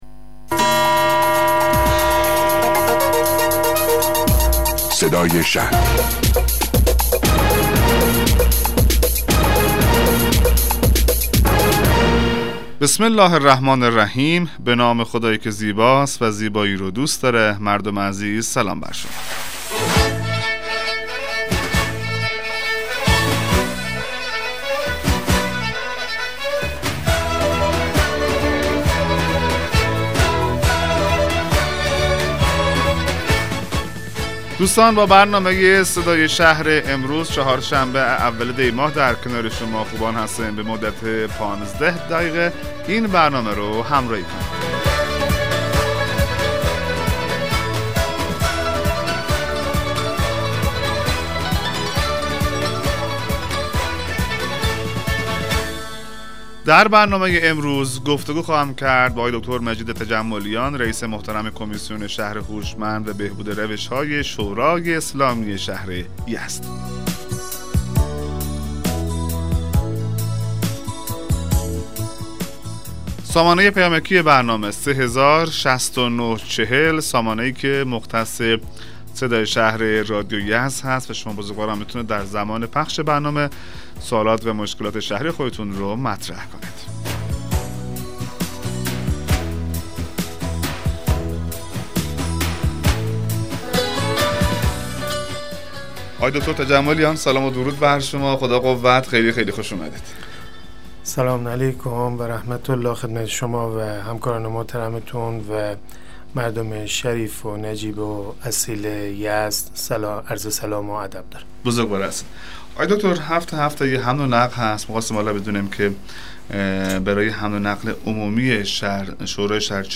مصاحبه رادیویی برنامه صدای شهر با حضور مجید تجملیان رییس کمیسیون شهر هوشمند و بهبود روشها شورای اسلامی شهر یزد